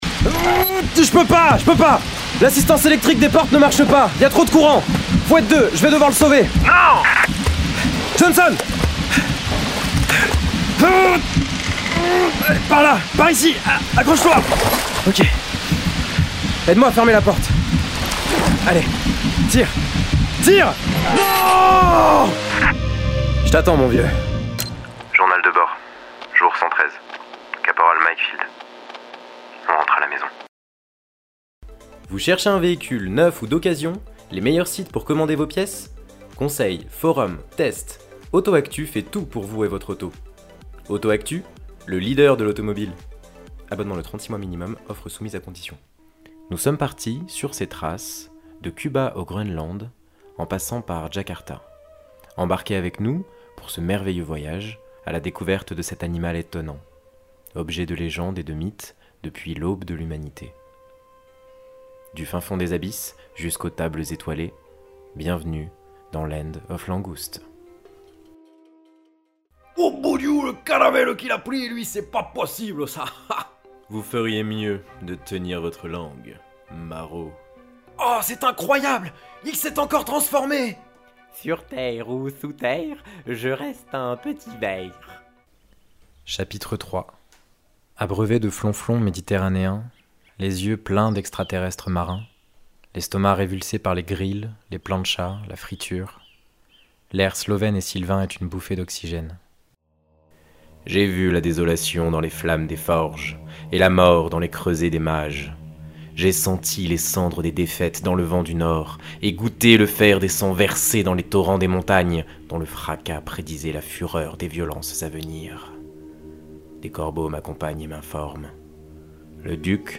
Bande démo vocale
Voix off